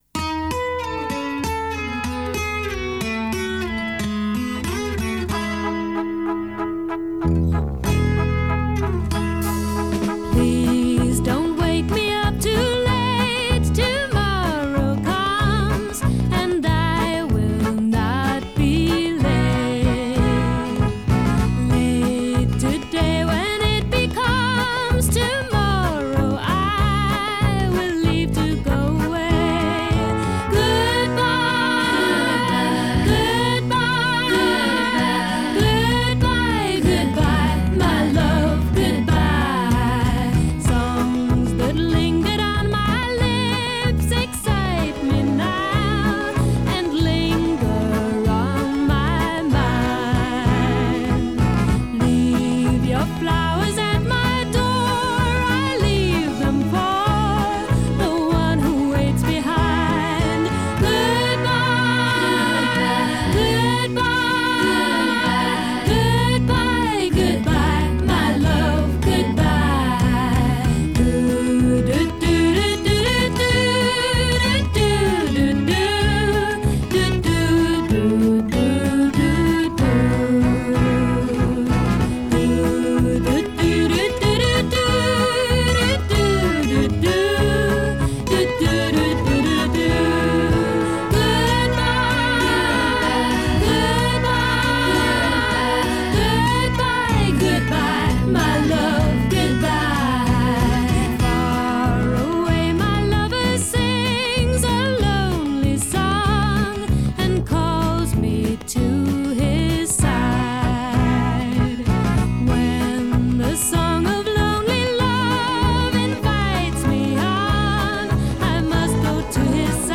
drums, strings, horns, Mellotron, and backing vocals
Intro 8   acoustic guitar followed by Mellotron chords
Refrain 8   solo vocal with multitracked responses b